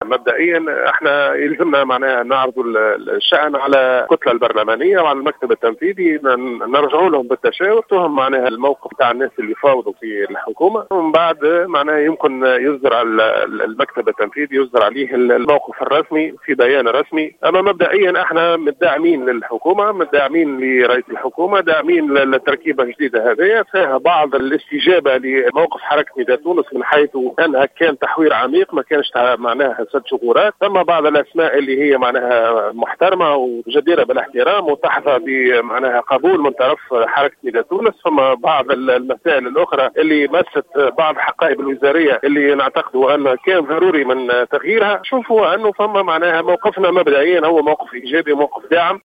وأضاف الحرباوي في تصريح للجوهرة اف ام، أن التركيبة الجديدة ضمت أسماء جديرة بالاحترام، كما طالت بعض الوزارات التي كان لا بد من تحويرها، مضيفا أن النداء مبدئيا داعما للحكومة ولرئيسها، وستجتمع كتلته البرلمانية ومكتبه التنفيذي للإعلان عن موقف الحزب الرسمي من التحوير الوزاري.